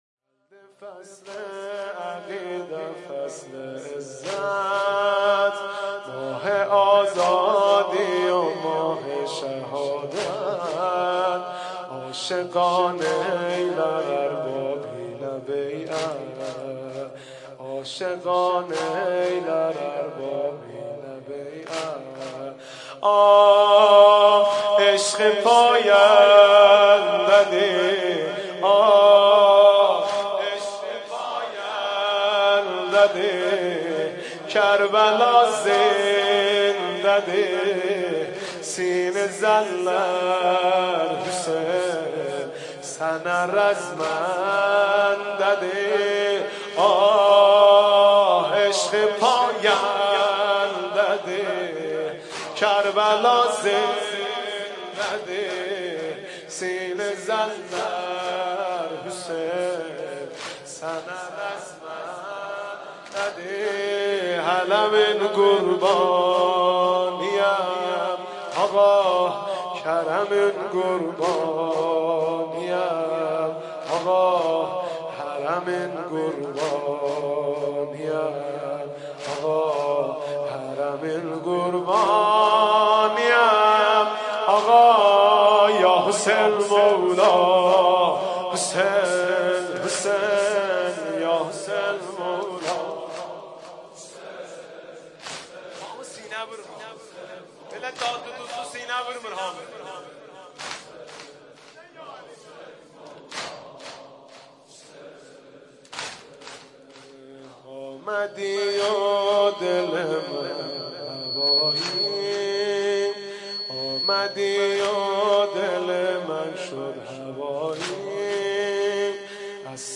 نوحه جديد مهدی رسولی, مداحی محرم مهدی رسولی